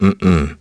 voices / heroes / en
Riheet-Vox-Deny-02.wav